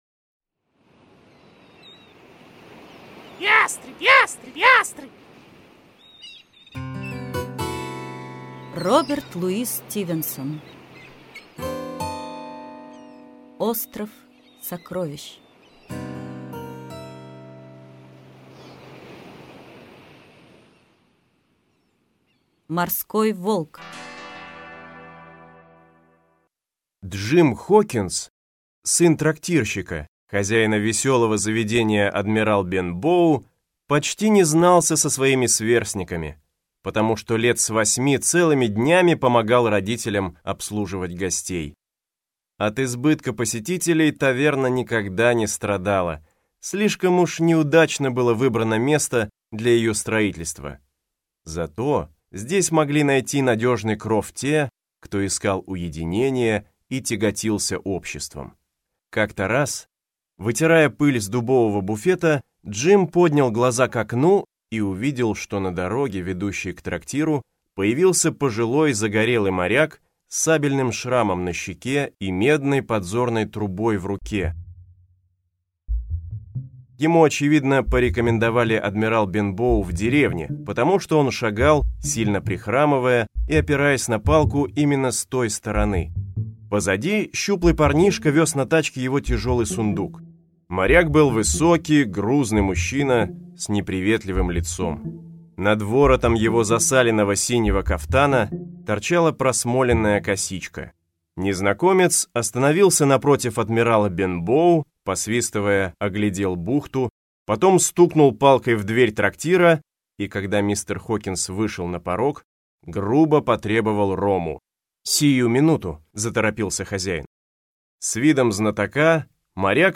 Аудиокнига Остров сокровищ (спектакль) | Библиотека аудиокниг